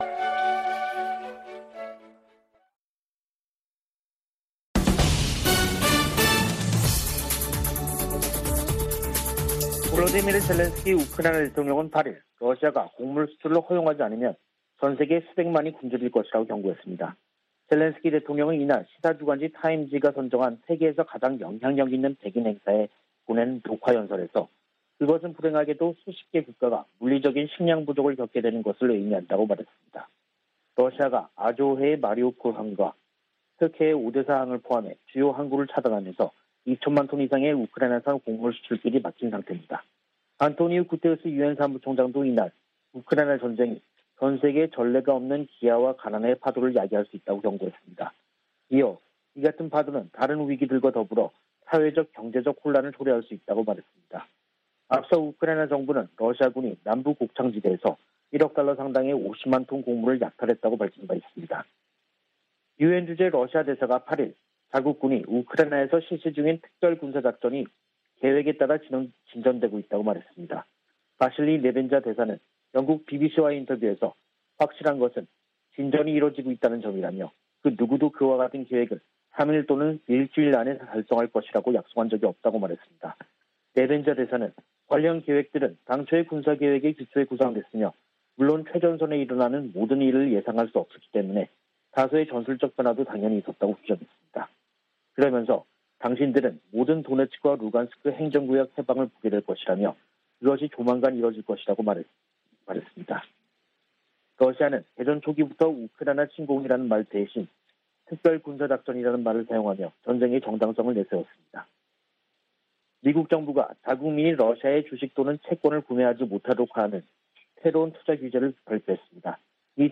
VOA 한국어 간판 뉴스 프로그램 '뉴스 투데이', 2022년 6월 9일 3부 방송입니다. 북한이 7차 핵실험을 강행하면 강력히 대응할 것이라는 방침을 백악관 고위당국자가 재확인했습니다. 미국의 B-1B 전략폭격기가 괌에 전진 배치됐습니다. 한국 외교부 김건 한반도평화교섭본부장과 중국의 류샤오밍 한반도사무특별대표가 전화로 최근 한반도 정세와 북한의 미사일 도발 대응 등에 의견을 교환했습니다.